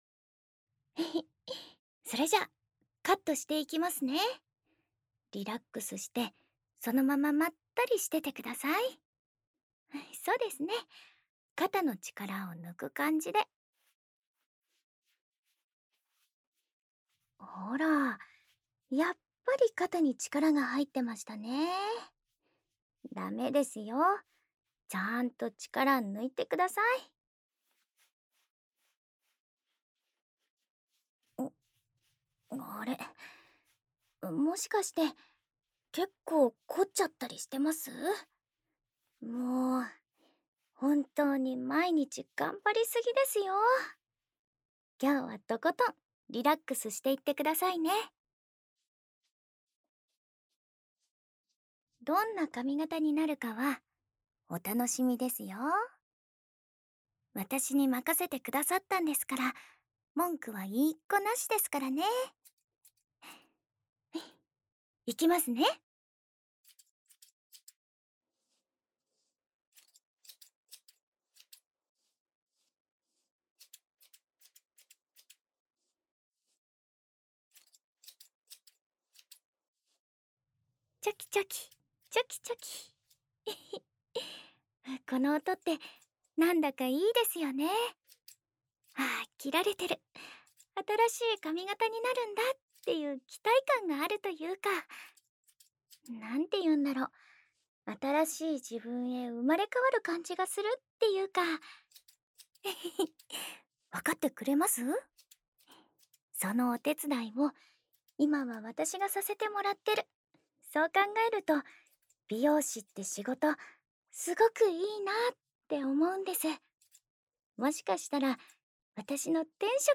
日常/生活 温馨 治愈 姐姐 环绕音 ASMR 低语
el62_03_『仔細剪髮的同時在耳邊悄聲聊天（兩耳）』.mp3